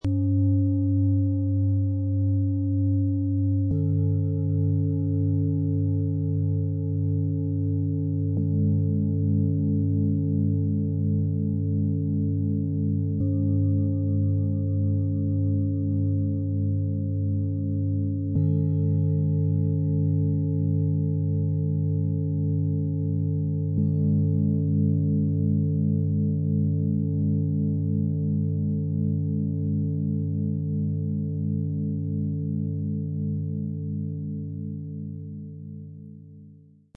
Ihr Klang wirkt sammelnd, ausgleichend und klar - ohne aufzuwühlen.
Die drei Klangschalen dieses Sets besitzen eine stabile, gut kontrollierbare Schwingung.
Ihr tiefer, voller Ton holt ab, vermittelt Ruhe und Sicherheit und unterstützt das Ankommen im Körper.
Ihr Klang ist freundlich, warm und kräftigend, mit einer sonnigen, ausgeglichenen Qualität.
Ihr Klang ist strahlend, harmonisch und freundlich, ohne nervös oder überstimulierend zu sein.
Im Sound-Player - Jetzt reinhören können Sie den Original-Ton genau dieser drei Klangschalen anhören. Das Set klingt ruhig, voll und zentrierend - sammelnd im Körper und klar in der Präsenz.
Bengalen-Schale, matt